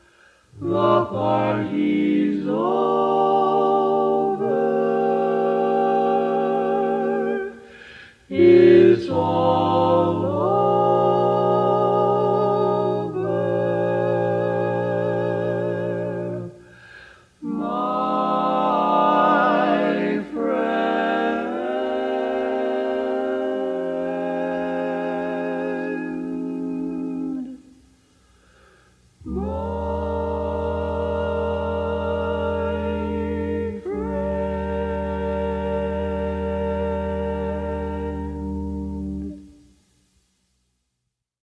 Key written in: E♭ Major
How many parts: 4
Type: Other male
All Parts mix: